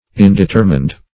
\In`de*ter"mined\